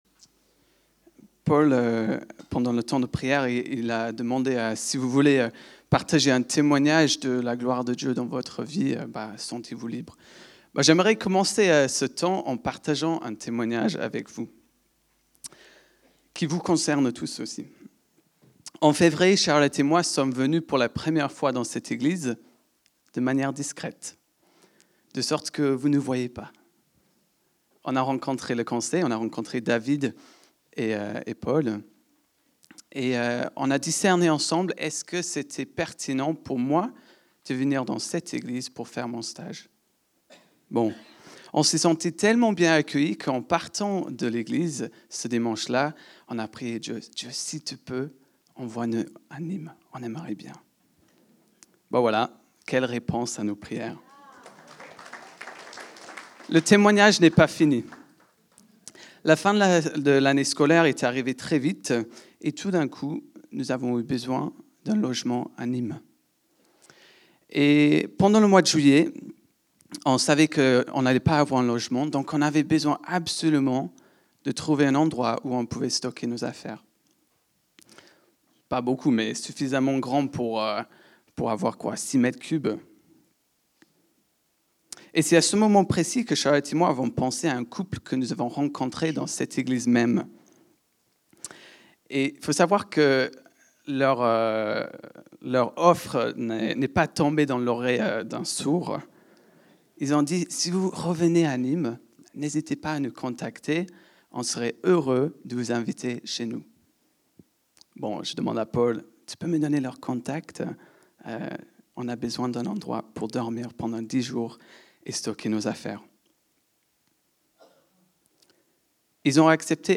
Culte du dimanche 14 septembre 2025, prédication